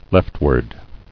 [left·ward]